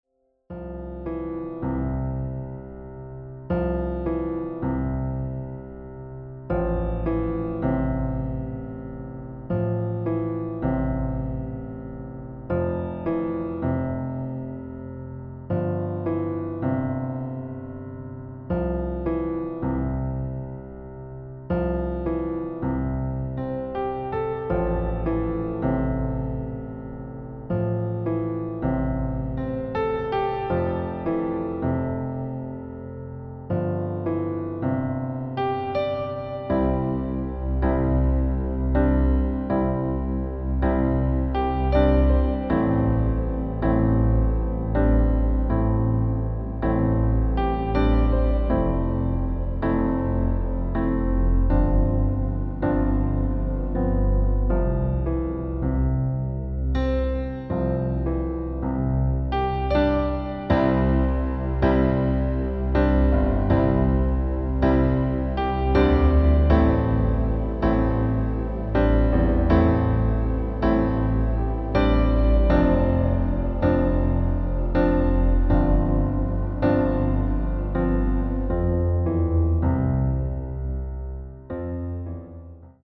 • Tonart: C Dur (Originaltonart ), D Dur, E Dur
Demo in C Dur:
• Das Instrumental beinhaltet NICHT die Leadstimme
Klavier / Streicher